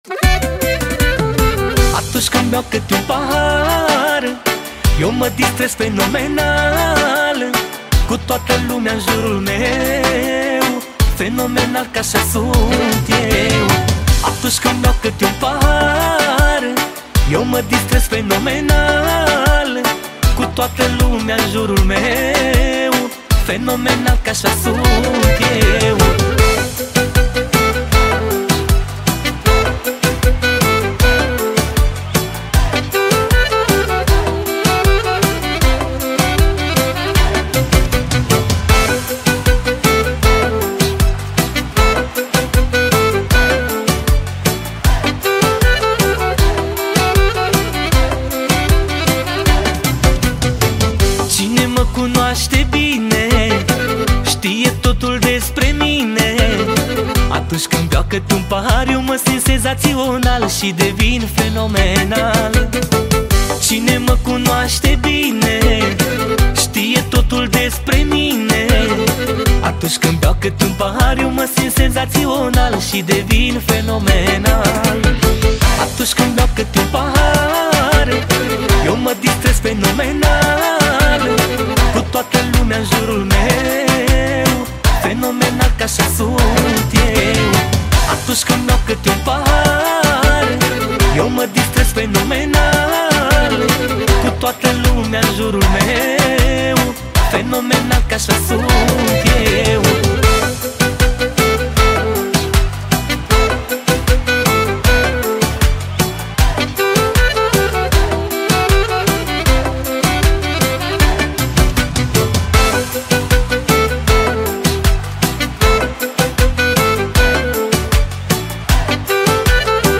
Data: 15.10.2024  Manele New-Live Hits: 0